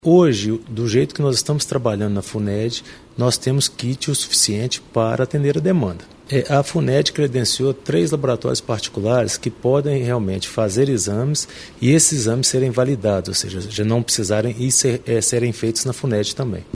Já o Secretário de Estado de Saúde de Minas Gerais, Carlos Eduardo Amaral diz que os kits que o governo do estado possui são suficientes para atender a demanda.